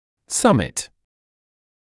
[‘sʌmɪt][‘самит]вершина, верхушка